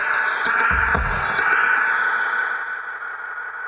Index of /musicradar/dub-designer-samples/130bpm/Beats
DD_BeatFXA_130-02.wav